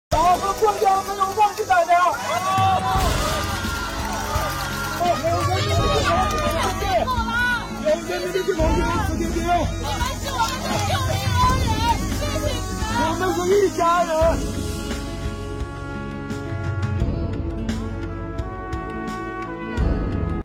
告别时，一段对话让人泪目